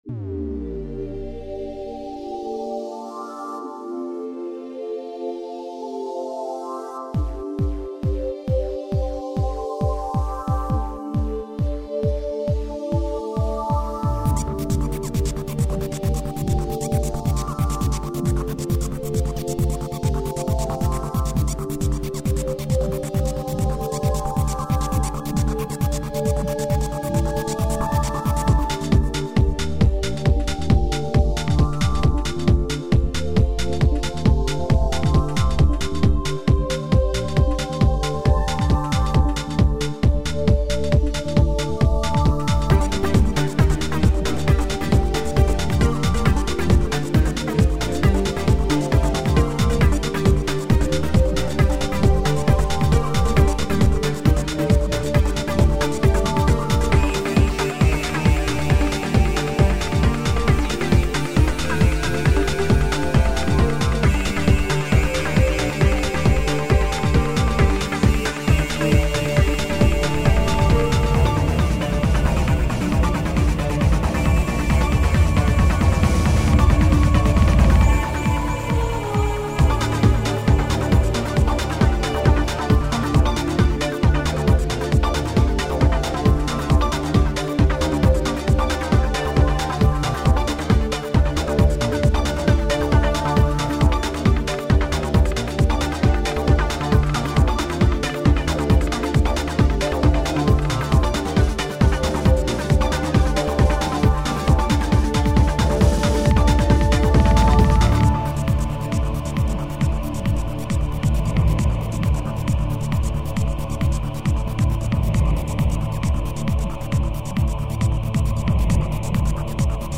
—Zoomin' sounds!
—Ethereal tones!
—Beats that make you bounce!